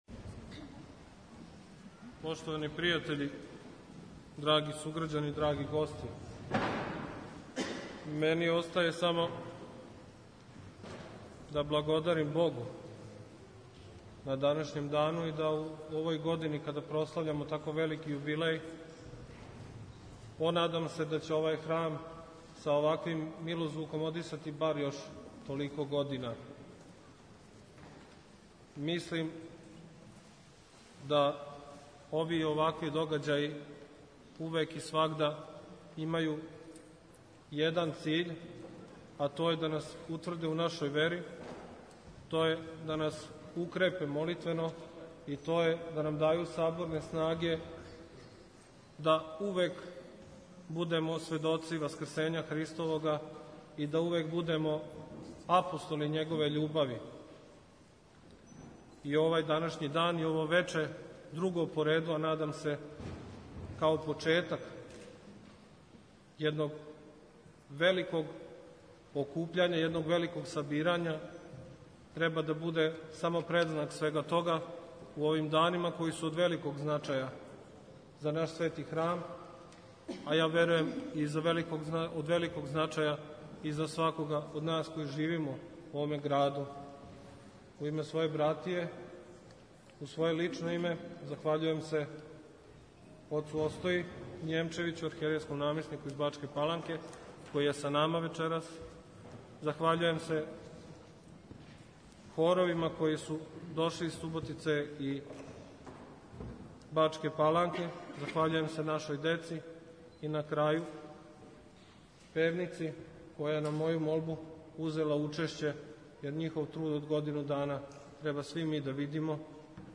У суботу, 4. јуна, у Саборној цркви у Сомбору, поводом 250 година од изградње храма Светог Великомученика Георгија, одржана је друга Смотра црквених хорова Епархије бачке.
Смотра је отпочела вечерњом службом коју су здружено отпојали сви учесници смотре, преко 80 хорских певача, а настављена је наизменичним представљањем хорова. На програму су била дела српске, руске, и грчке православне музике из различитих периода историје Цркве.
У нади да ће се и убудуће хорови састајати на овом месту, учесници су смотру завршили заједничким појањем Многољетија.